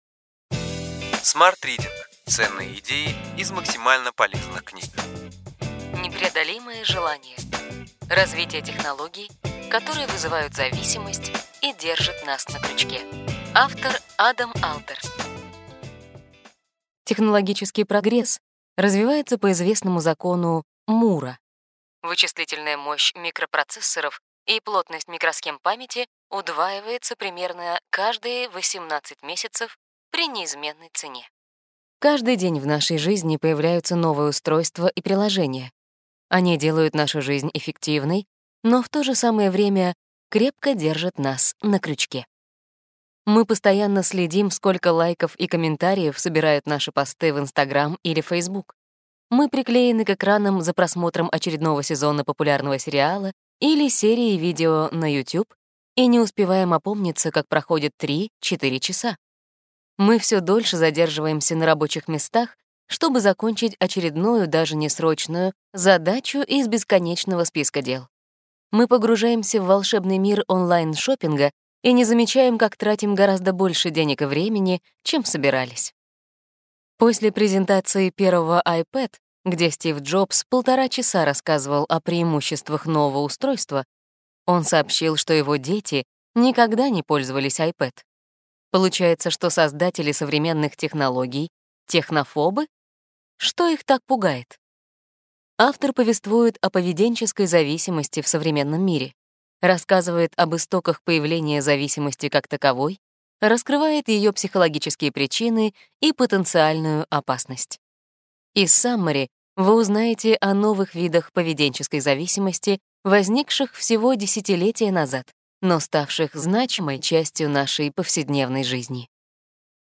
Аудиокнига Ключевые идеи книги: Непреодолимое желание. Развитие технологий, которые вызывают зависимость и держат нас на крючке.